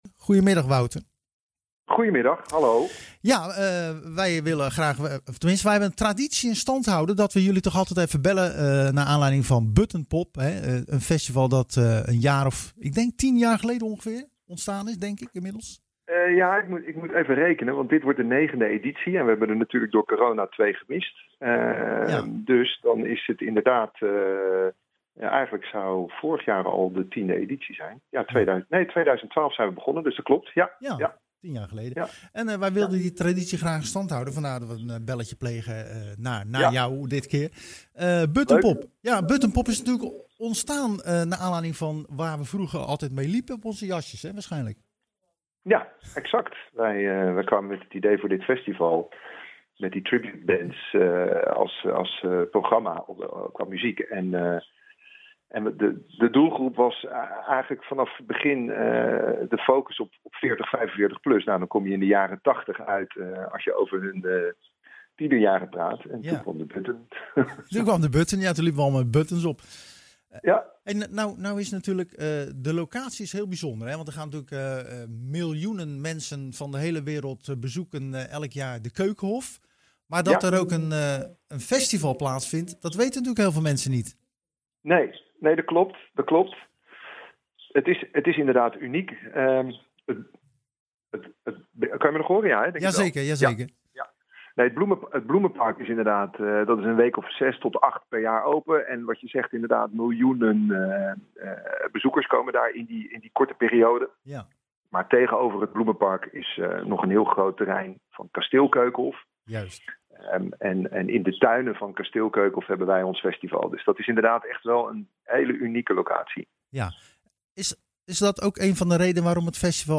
We belden tijdens het programma Zwaardvis met de organisatie van Buttonpop.Dit is een jaarlijkse festival met tributebands uit heel Europa en vindt plaats in de Keukenhof in Lisse. Het festival is ieder jaar uitverkocht maar uitbreiding naar meerdere dagen is niet mogelijk op last van de gemeente Lisse.� ��